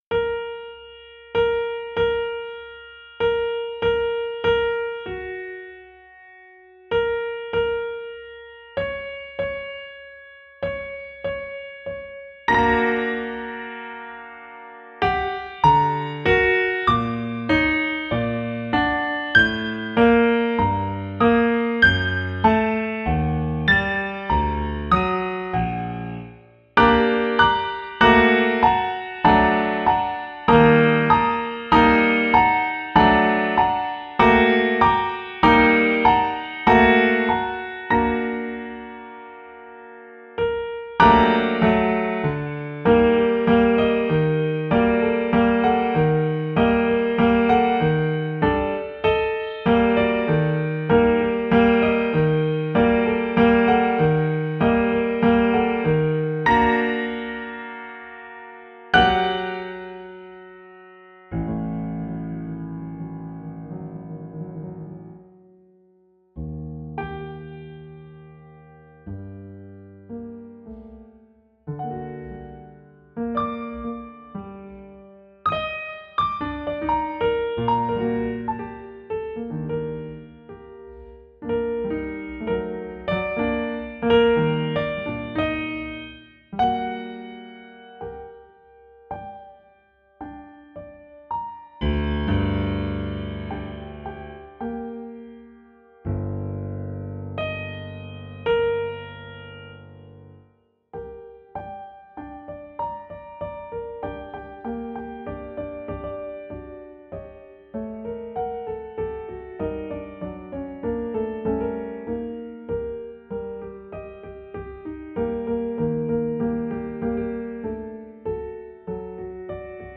Dangerous Neighbors - solo piano - Fear Horror and Loss - Zystrix.mp3